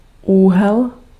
Ääntäminen
IPA: /ɑ̃ɡl/